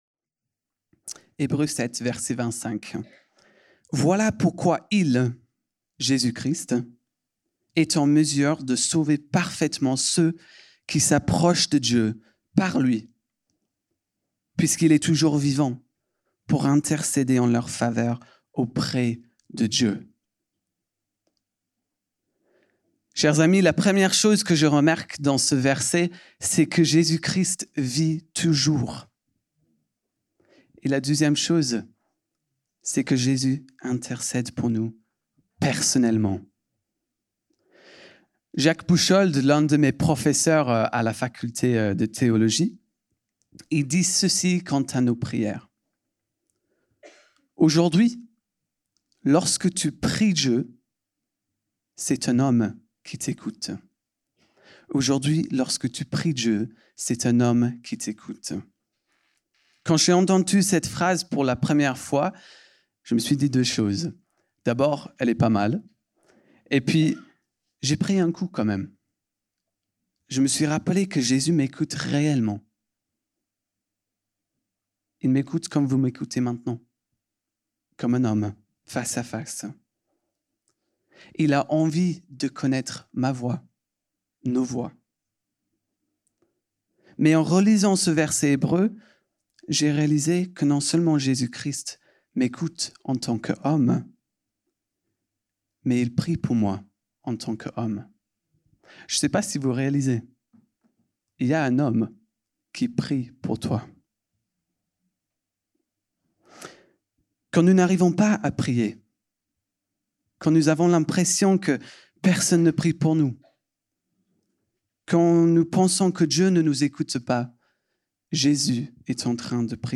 Culte du dimanche 30 novembre 2025, prédication